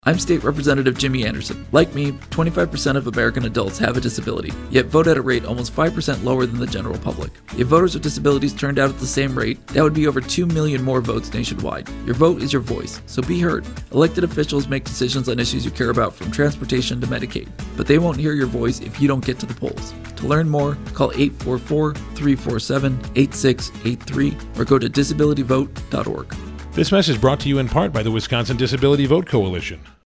The DVC partnered with State Representative Jimmy Anderson and 92.7 FM/1580 AM to produce two public service announcements on the importance of people with disabilities participating in all elections and making a plan to vote. Representative Anderson believes strongly in making sure that all people with disabilities can fully participate in their civic duty, so he has partnered with the Disability Vote Coalition to help educate the public on voting rights and the importance of having your voice heard through voting.